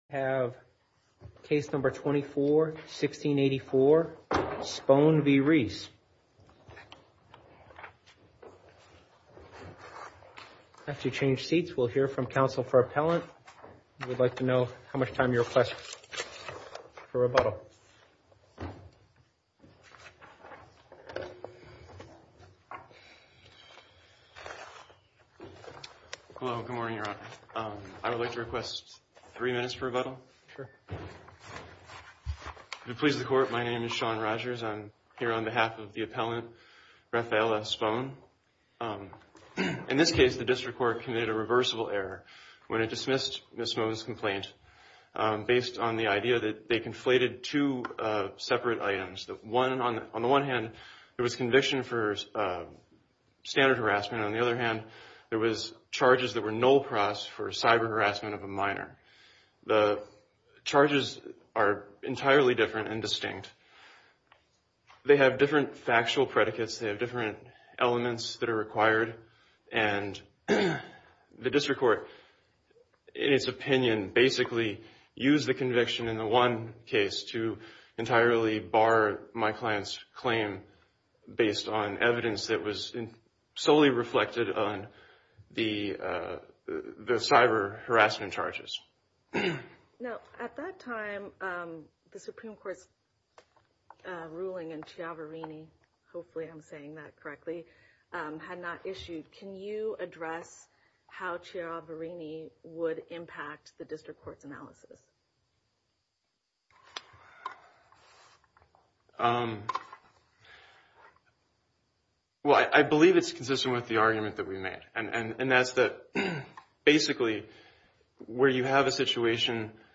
Oral Arguments For The Court Of Appeals For The Third Circuit